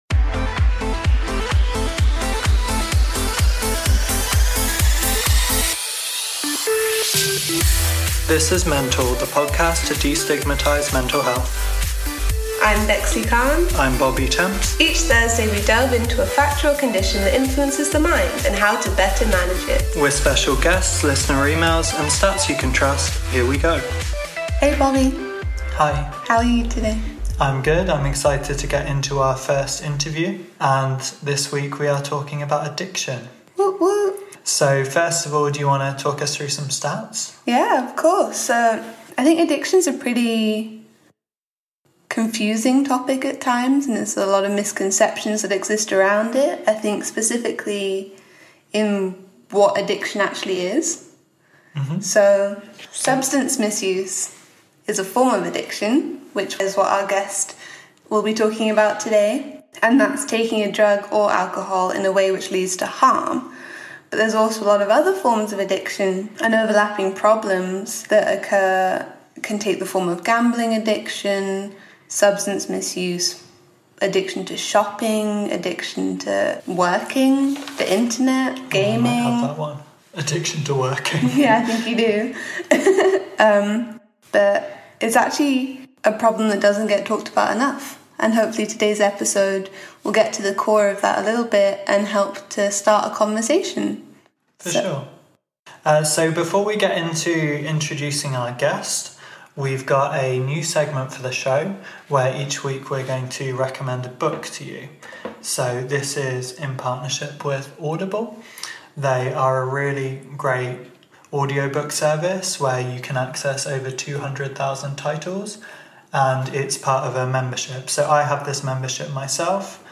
First Interview !